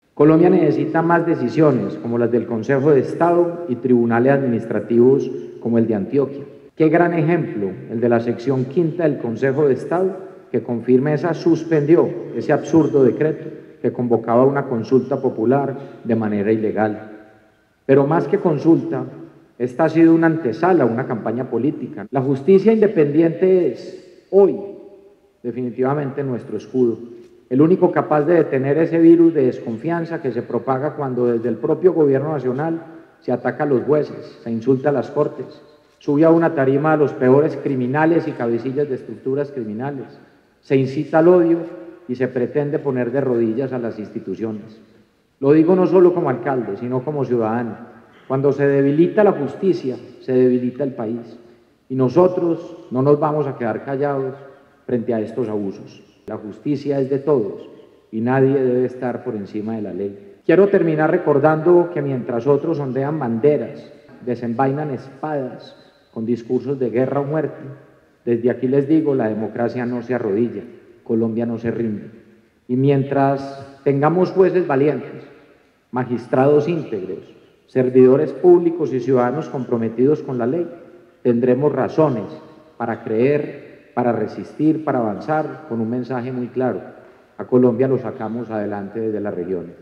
En su participación en la rendición de cuentas del Tribunal Administrativo de Antioquia, el alcalde Federico Gutiérrez Zuluaga respaldó a los jueces de la República y los alentó a seguir trabajando por la justicia con total independencia.
Declaraciones-alcalde-de-Medellin-Federico-Gutierrez-2.mp3